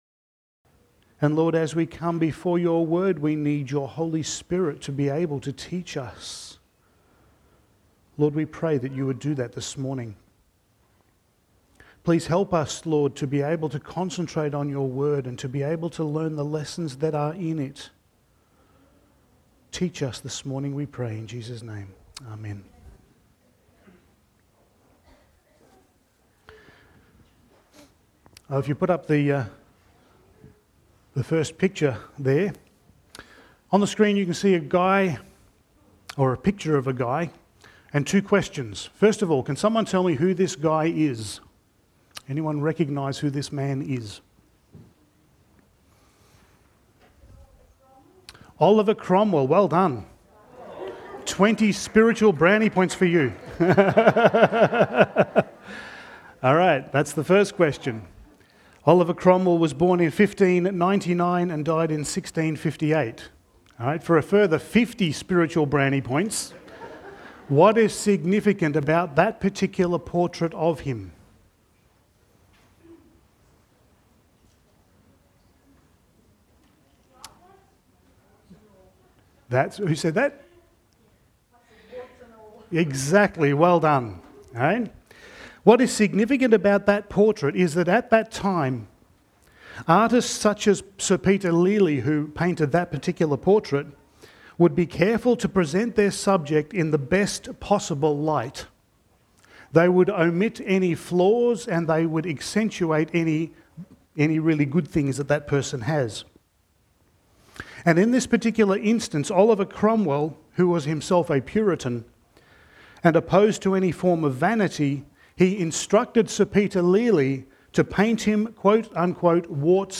Passage: Judges 8:29-9:57 Service Type: Sunday Morning